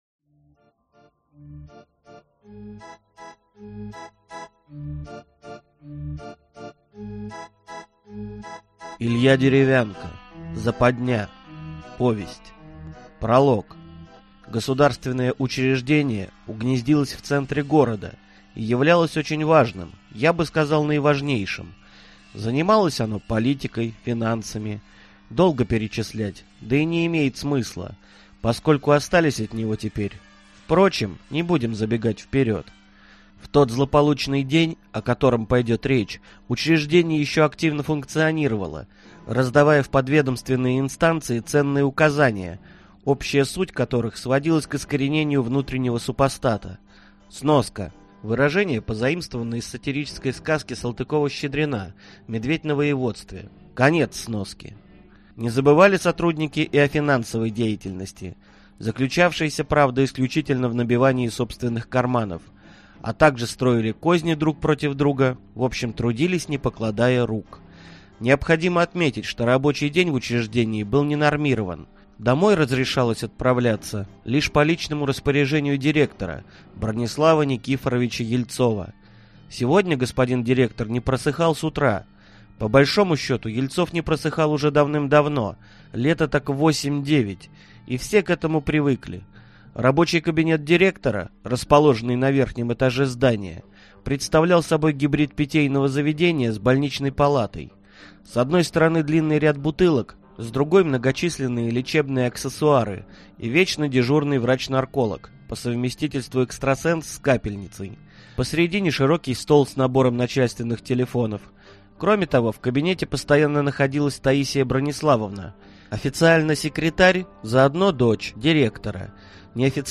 Аудиокнига